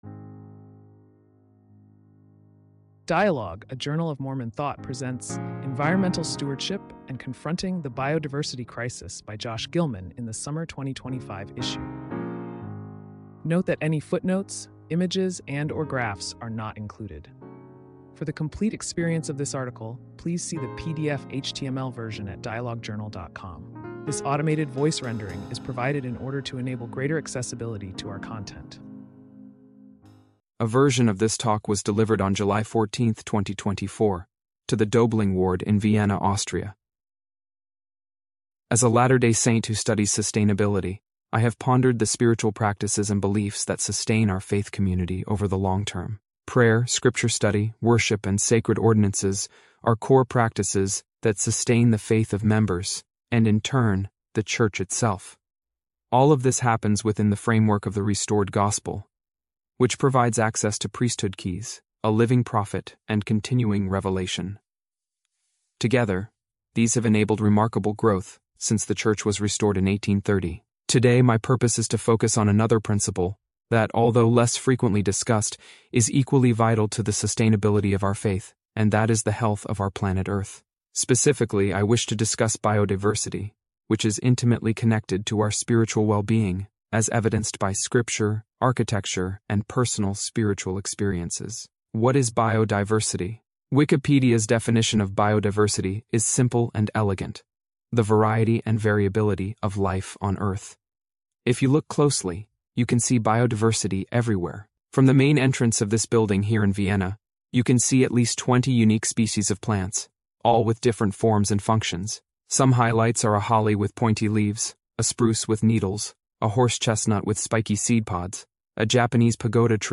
This automated voice rendering is provided in order to enable greater…